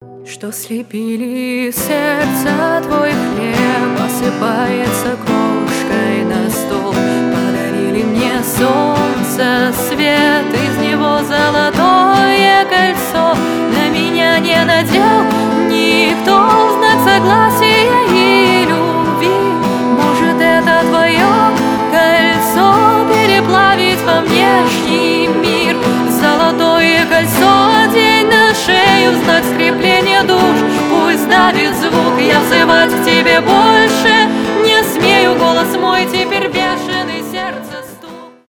фолк
красивый женский голос , акустика , поп